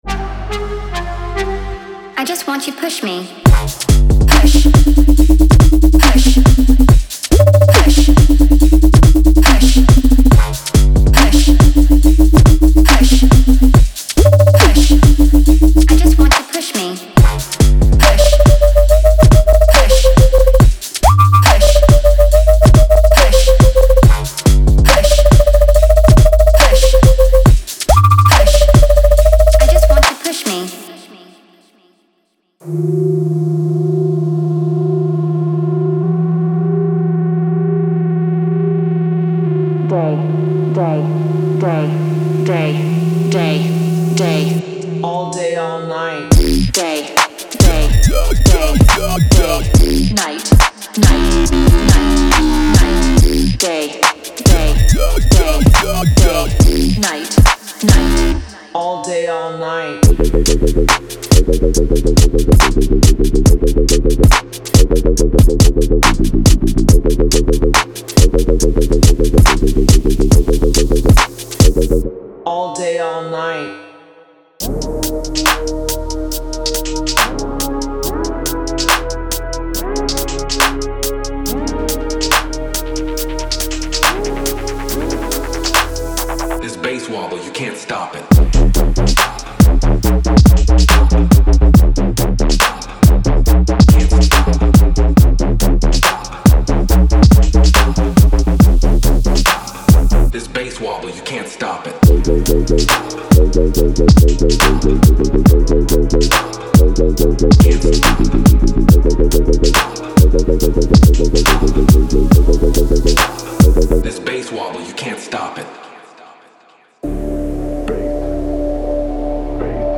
Genre:Dubstep
粗く、革新的で、重厚感あふれるサウンドです。
パック内では、迫力のあるベースラインが意図的に歪み、ワブルし、ダンスフロアを支配する精密さを備えています。
ステレオフィールド全体に広がるアンビエンスやムーディなパッドが、期待感に満ちた影の空間を作り出します。
デモサウンドはコチラ↓